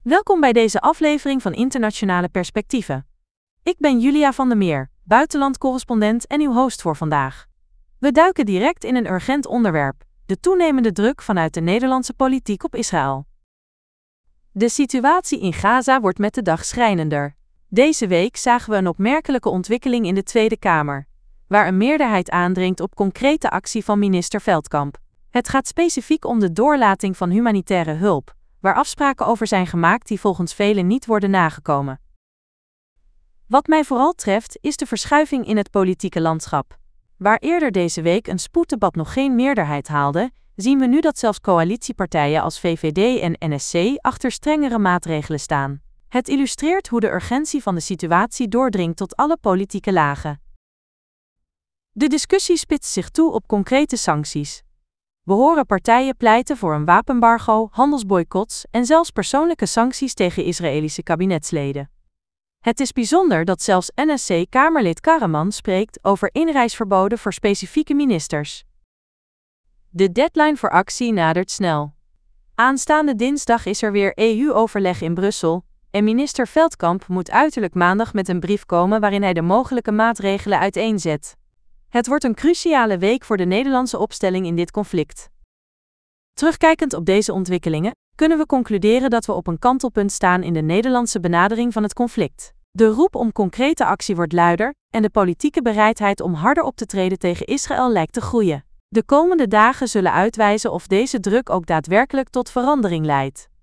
Podcast gegenereerd van tekst content (2216 karakters)